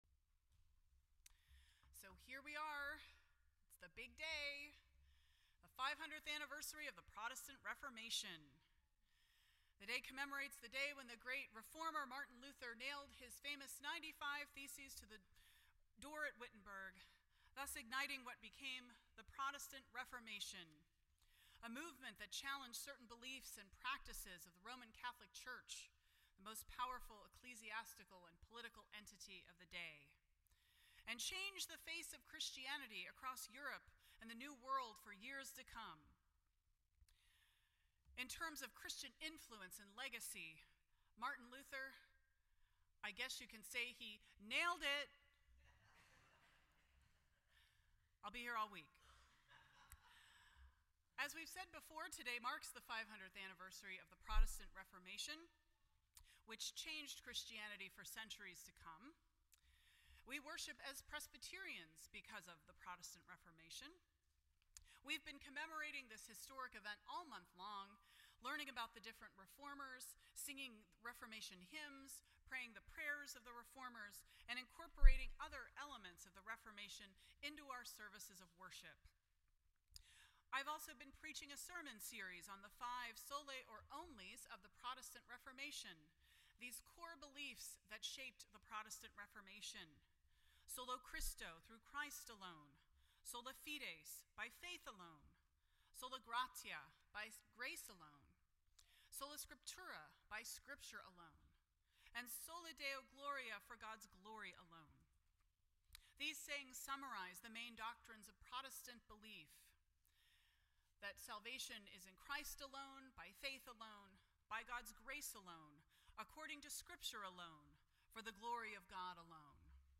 None Service Type: Reformation Sunday %todo_render% Share This Story